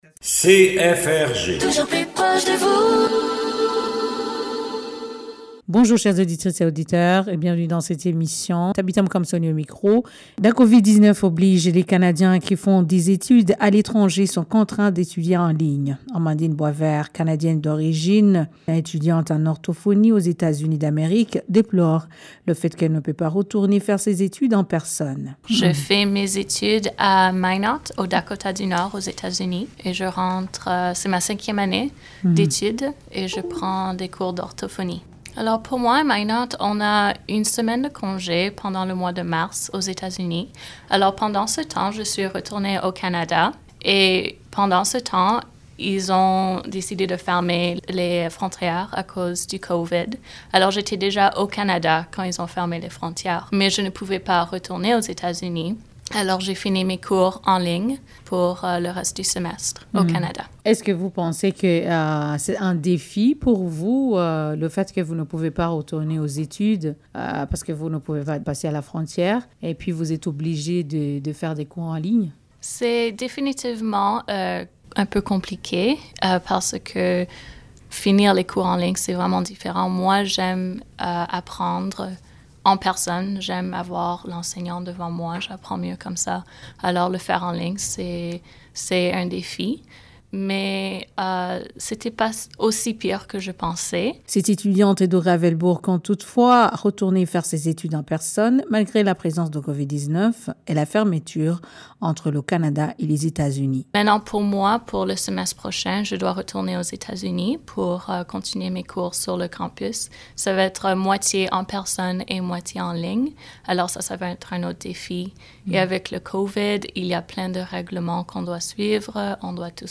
Elle pense toutefois retourner aux États-Unis malgré la fermeture de frontières entre le Canada et les E.U. Retrouvez tout dans cette émission